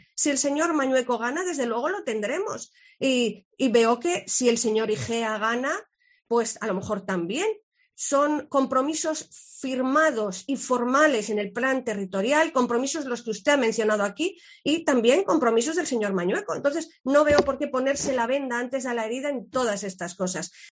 Sonsoles Sánchez-Reyes, portavoz PP. 13F